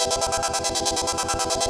SaS_MovingPad02_140-E.wav